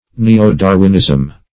Neo-Darwinism \Ne`o-Dar"win*ism\, n.